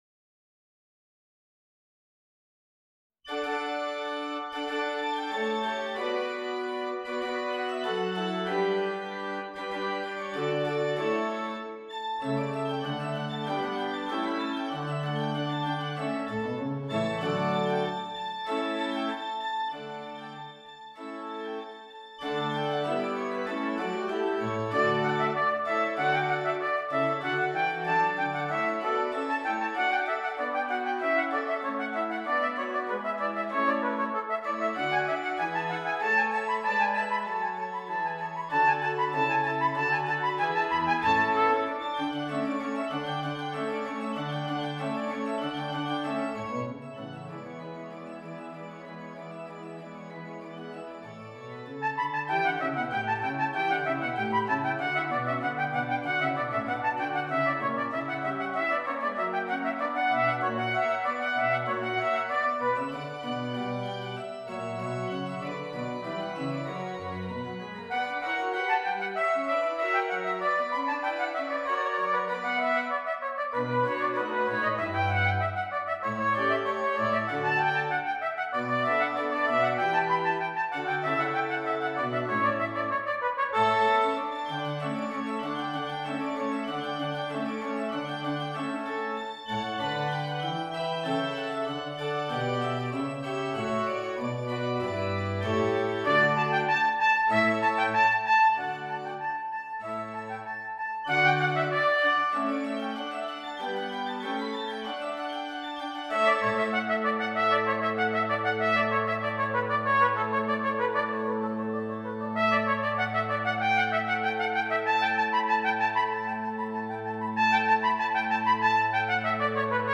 Trumpet and Keyboard